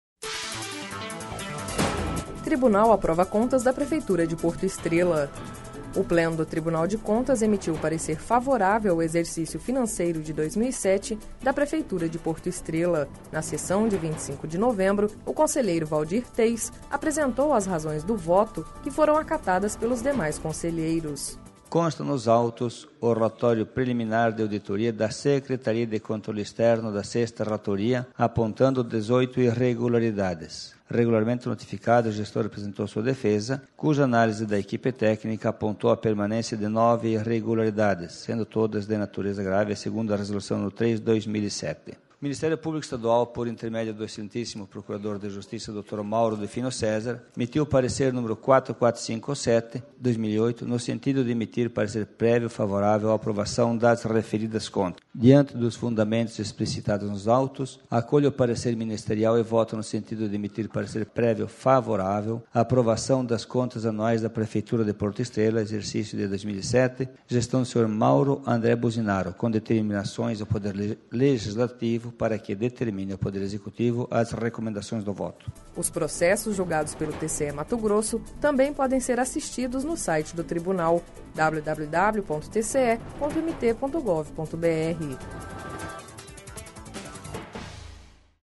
Sonora: Waldir Teis – conselheiro TCE-MT